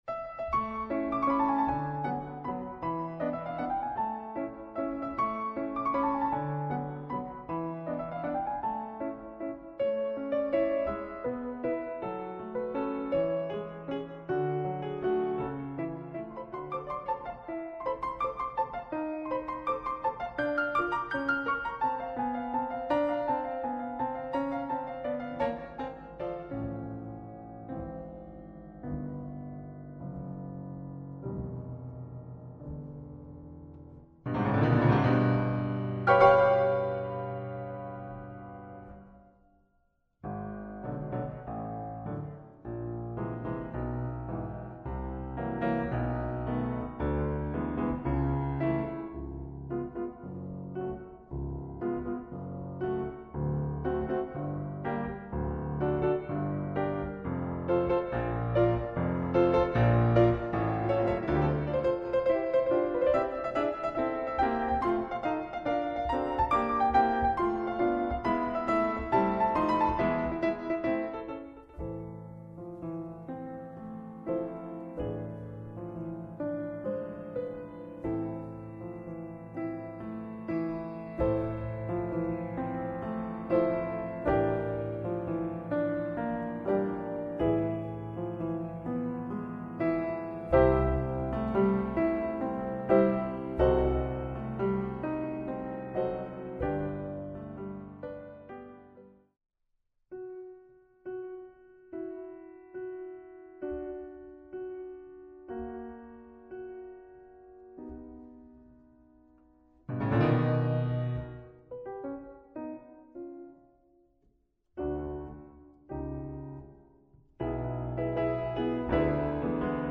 Ein musikalisches Tanzspiel für Kinder ausgestattet mit CD